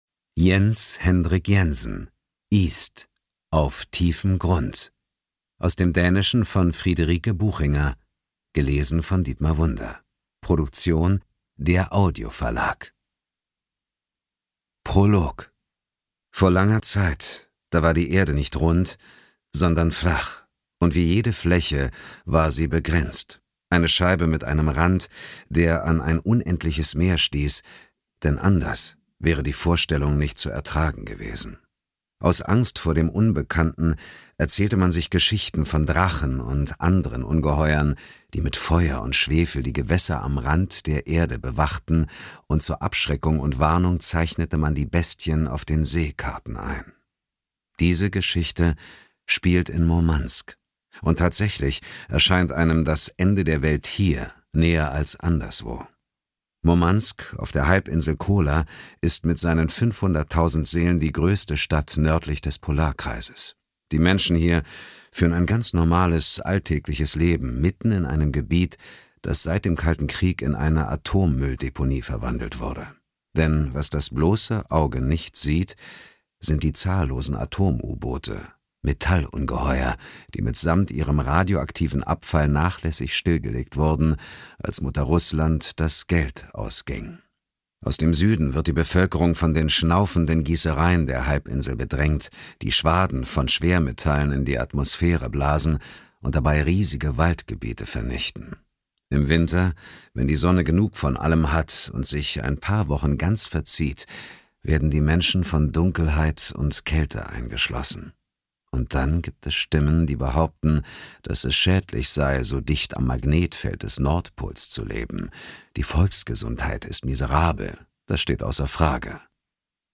Dietmar Wunder (Sprecher)